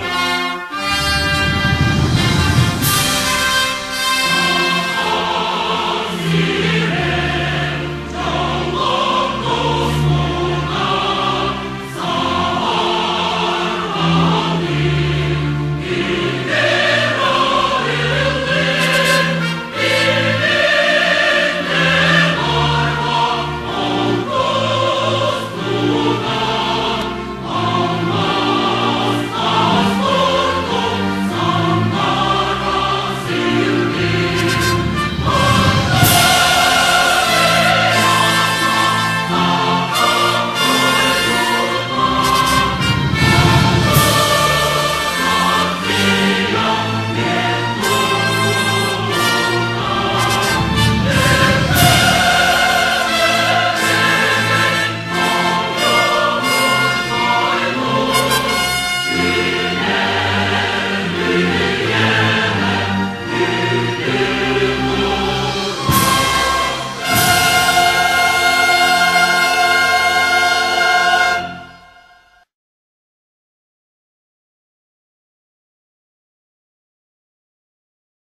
Звучит Гимн РС (Я) (презентация приостанавливается)